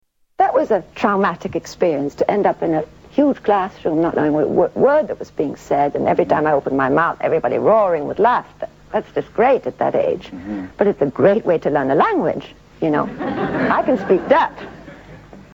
Tags: Audrey Hepburn clips Audrey Hepburn interview Audrey Hepburn audio Audrey Hepburn Actress